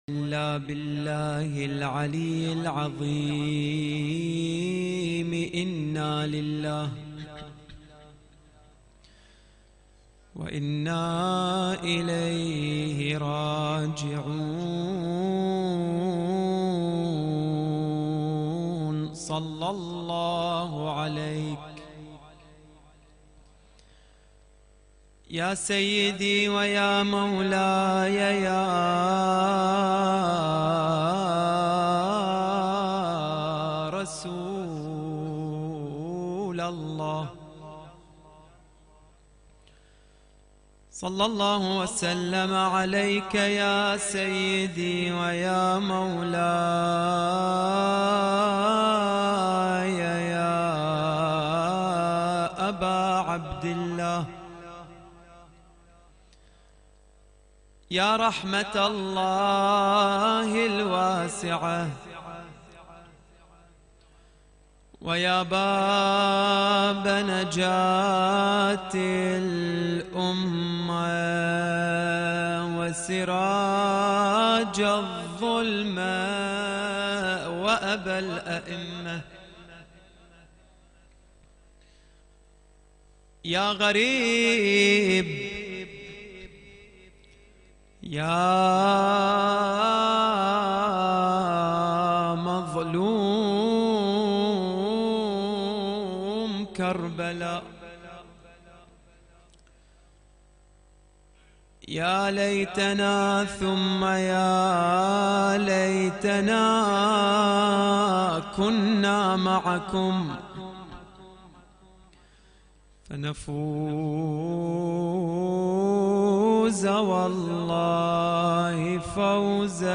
تغطية شاملة: مجلس ليلة 4 صفر ضمن الليالي الحسنية 1441هـ